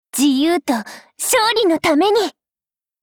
Cv-10710_battlewarcry.mp3